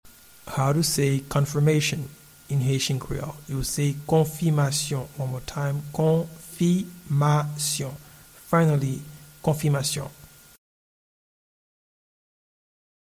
Pronunciation and Transcript:
Confirmation-in-Haitian-Creole-Konfimasyon.mp3